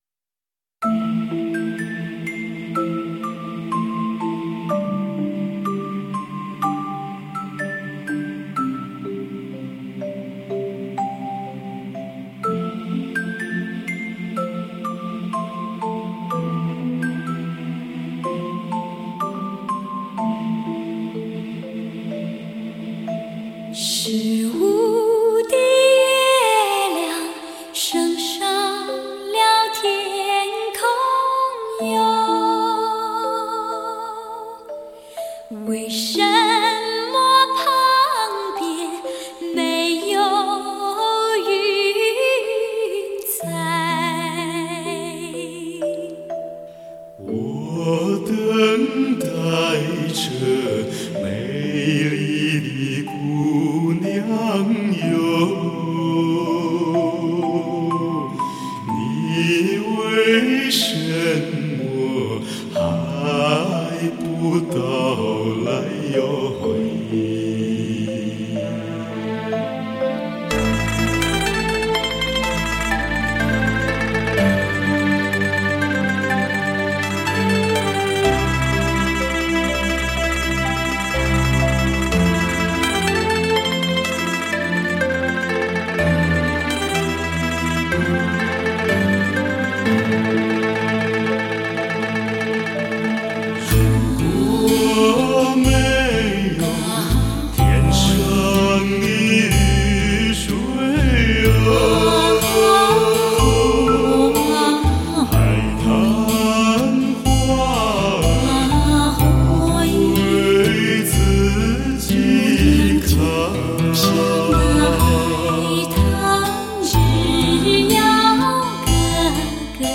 动听绝伦的发烧极品，顶级专业发烧器材的试音精品。